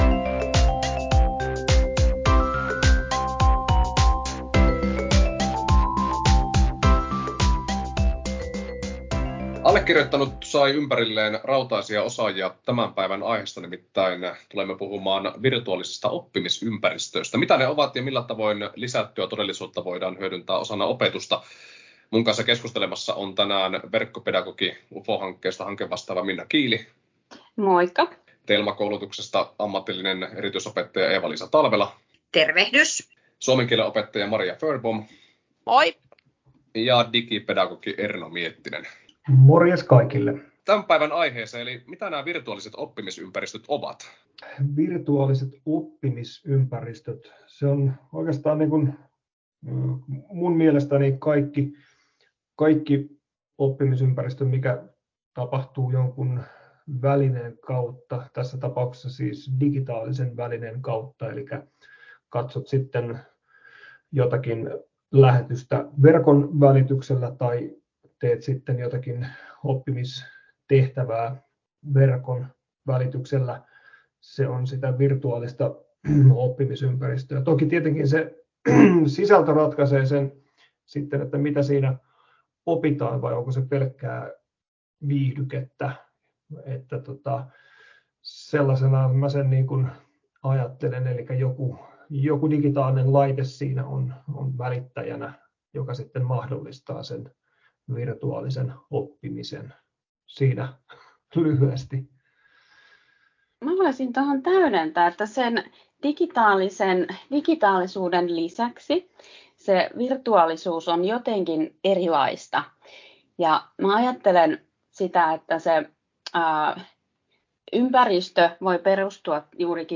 UFO-hanke laskeutui pohtimaan näitä kysymyksiä lyhyen juttutuokion verran